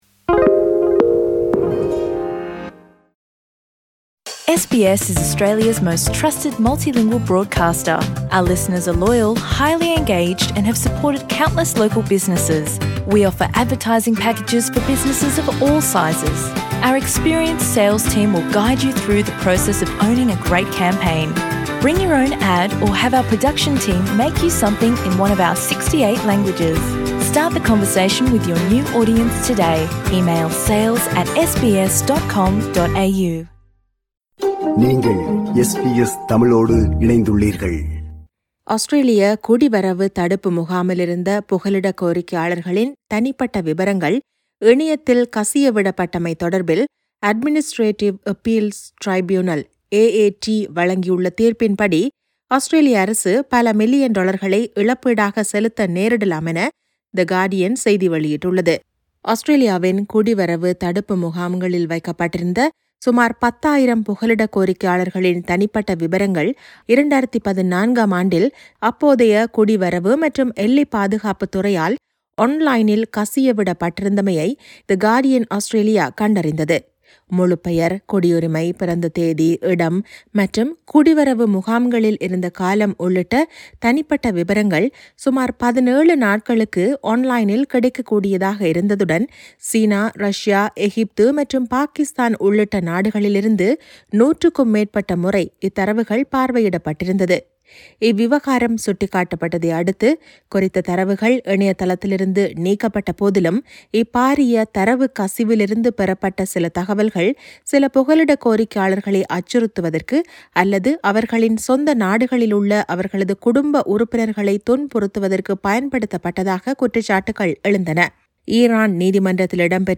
குடிவரவுத் தடுப்பு முகாமிலிருந்த புகலிடக் கோரிக்கையாளர்களின் தனிப்பட்ட விவரங்கள் இணையத்தில் கசியவிடப்பட்டமை தொடர்பில் Administrative Appeals Tribunal வழங்கியுள்ள தீர்ப்பின்படி ஆஸ்திரேலிய அரசு பல மில்லியன் டொலர்களை இழப்பீடாகச் செலுத்த நேரிடலாம் என தெரிவிக்கப்படுகிறது. இதுகுறித்த செய்தி விவரணத்தை முன்வைக்கிறார்